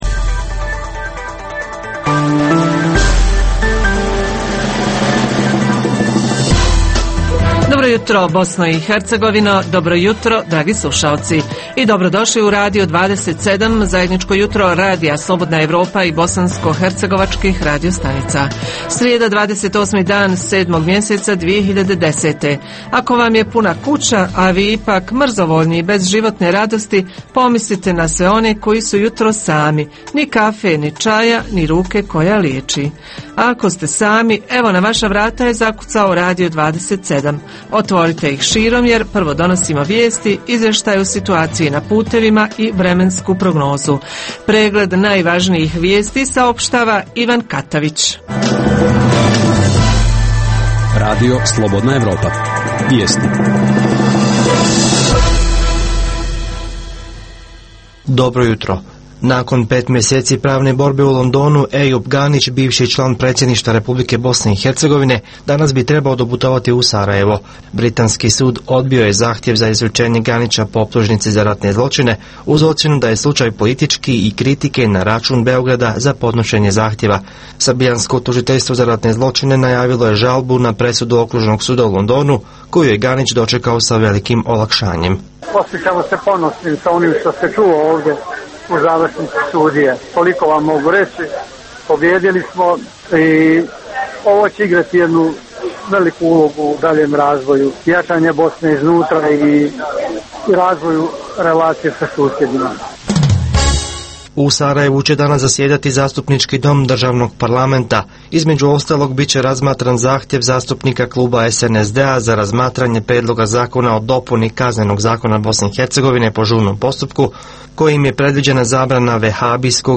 Vijesti emitujemo na početku, na sredini i pred kraj Jutarnjeg programa. Tema: Legende i istorijske činjenice o nastanku naziva bh. gradova. Reporteri javljaju kako su nastala imena gradova : Banjaluka, Tuzla, Zvornik te jezero Balkana kod Mrkonjić Grada. Iz Kotorskog kod Doboja možete čuti reportažu o tradicionalnoj manifestaciji „Baška pohod“ i kako je nastala ova zanimljiva ljetna priredba. U pravnim savjetima, advokat odgovara na pitanje slušateljke vezano za njena prava nastala na osnovu Ugovora o izdržavanju.